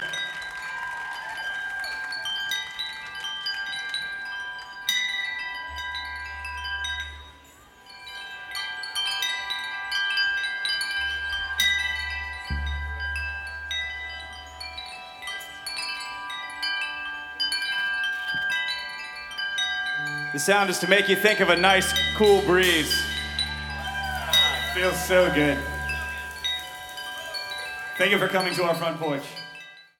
Cajun/Zydeco
Indie
Pop
Rock